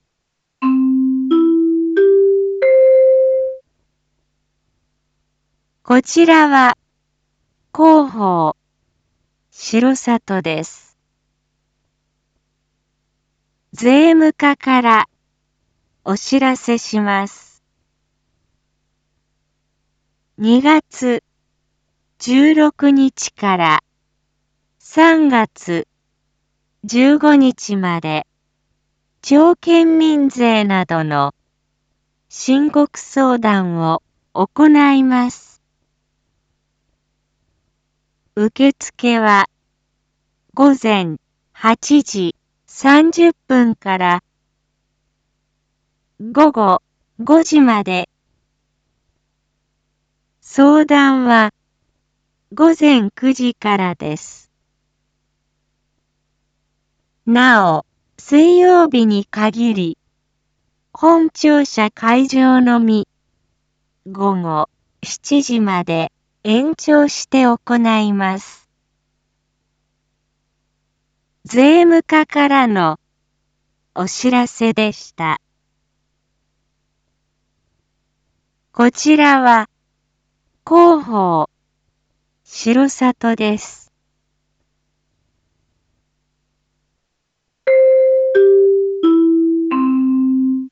Back Home 一般放送情報 音声放送 再生 一般放送情報 登録日時：2022-03-09 07:01:37 タイトル：申告相談のご案内 インフォメーション：こちらは広報しろさとです。